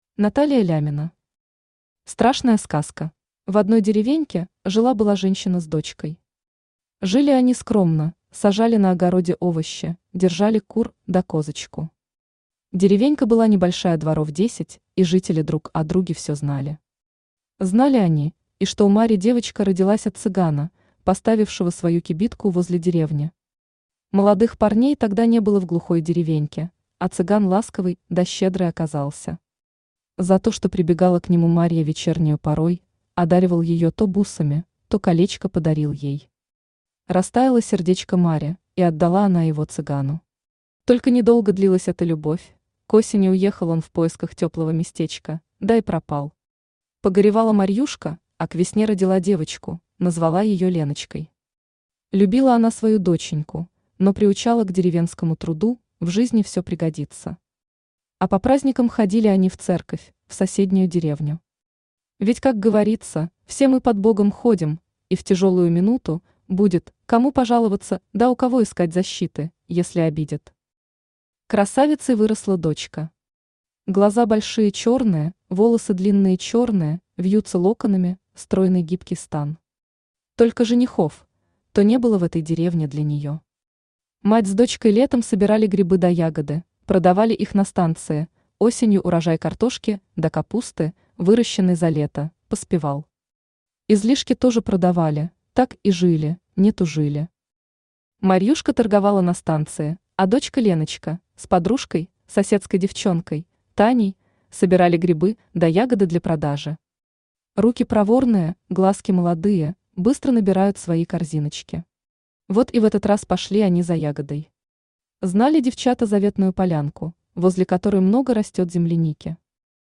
Аудиокнига Страшная сказка | Библиотека аудиокниг
Aудиокнига Страшная сказка Автор Наталия Леонидовна Лямина Читает аудиокнигу Авточтец ЛитРес.